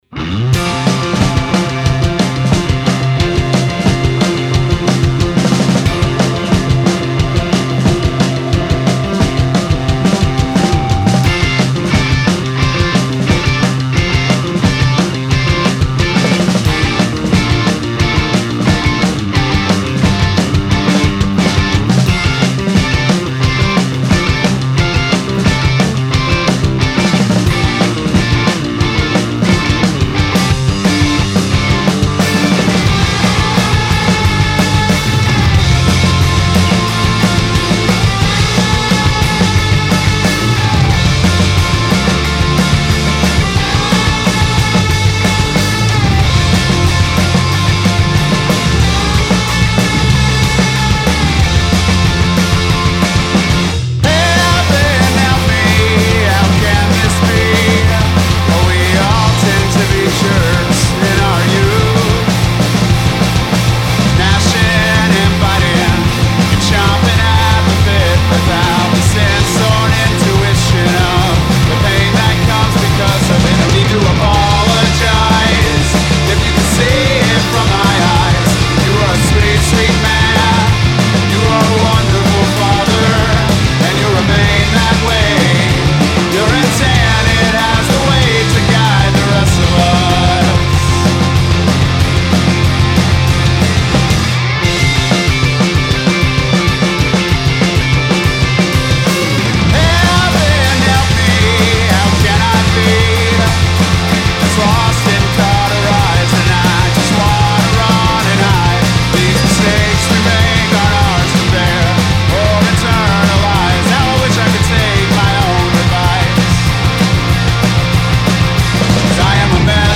Guitars, Vocals, Keys
Drums
Bass
Cello
Trombone